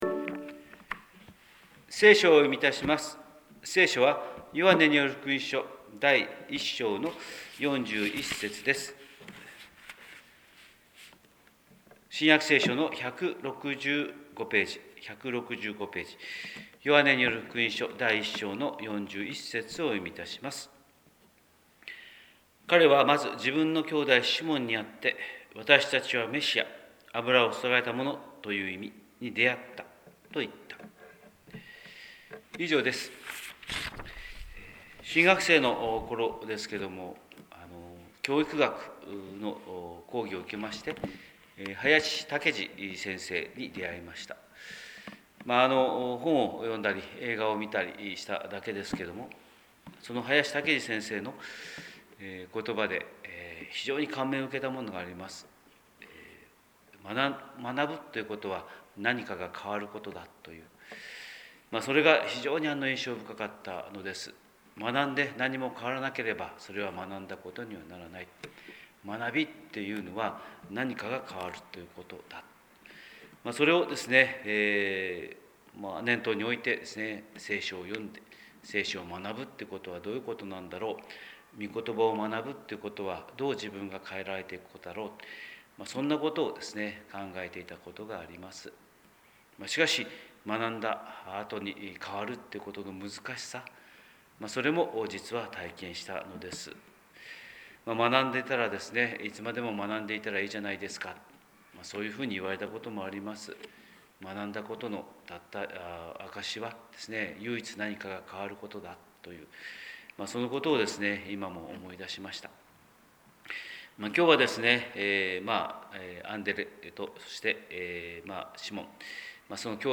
神様の色鉛筆（音声説教）: 広島教会朝礼拝241114
広島教会朝礼拝241114「すぐに伝える」